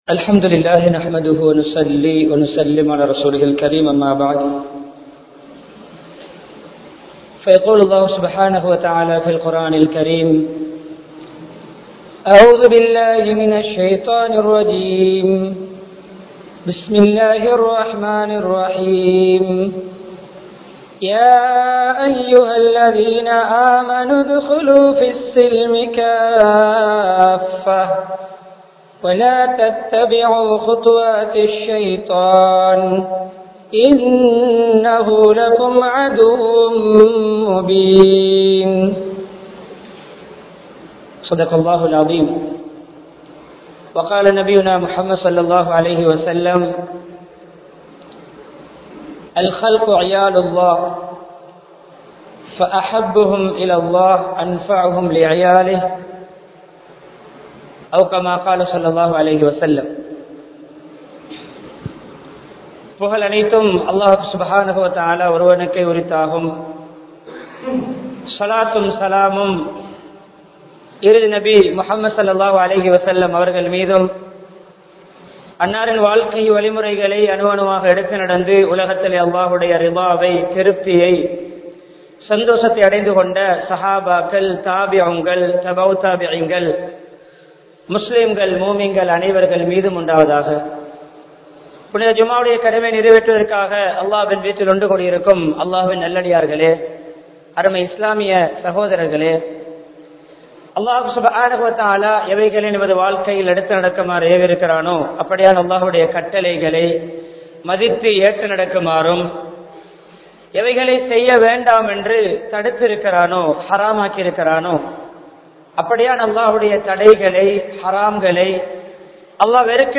Islaththil Uruthiyaha Iruppoam (இஸ்லாத்தில் உறுதியாக இருப்போம்) | Audio Bayans | All Ceylon Muslim Youth Community | Addalaichenai
Town Jumua Masjidh